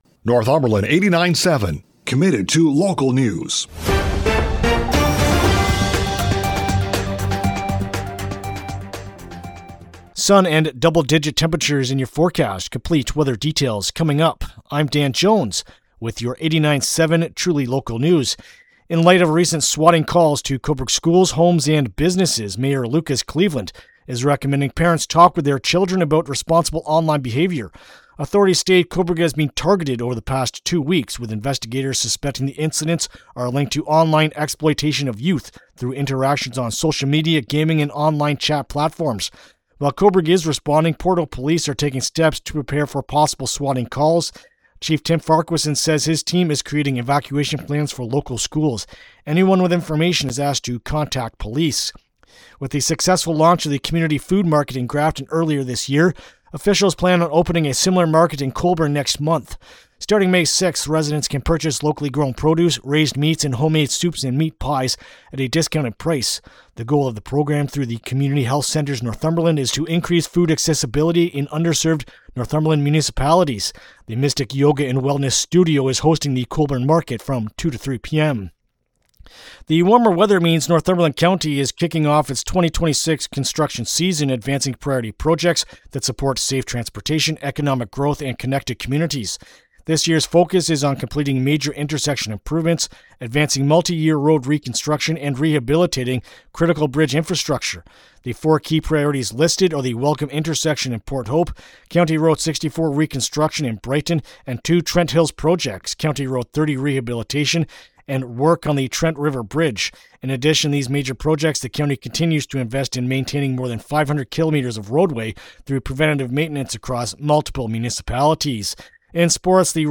Thursday-April-23-AM-News-1.mp3